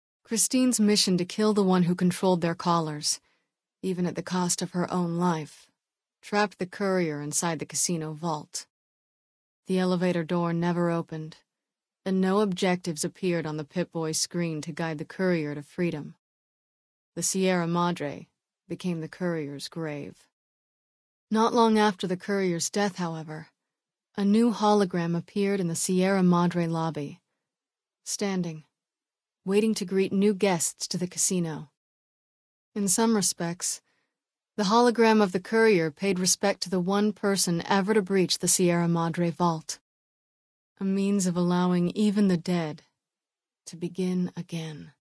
295 KB Category:Dead Money endgame narrations 1